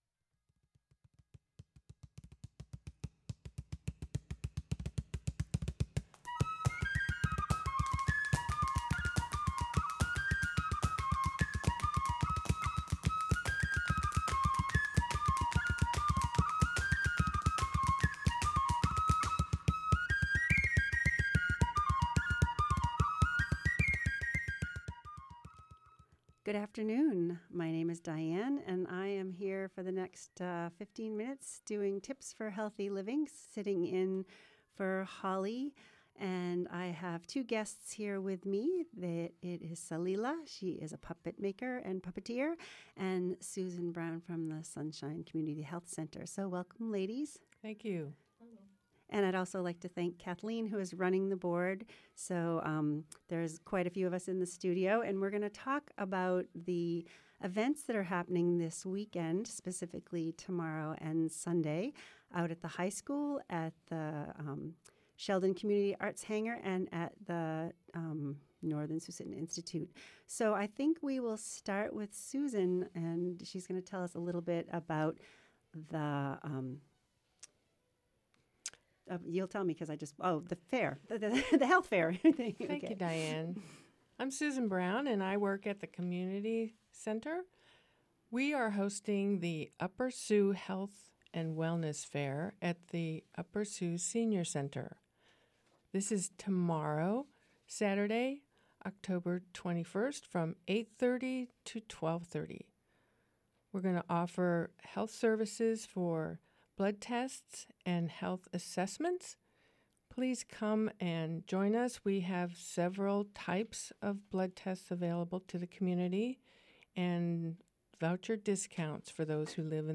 A live 15-minute conversation about health and wellness from health care providers in our communities.